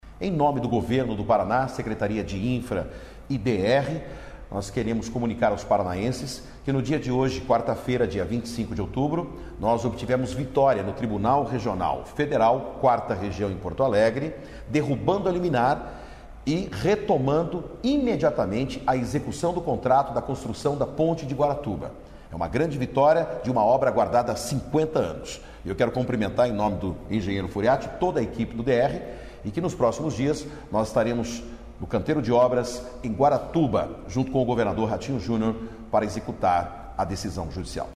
Sonora do secretário de Infraestrutura e Logística, Sandro Alex, sobre a liberação das obras da Ponte de Guaratuba